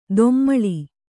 ♪ dommaḷi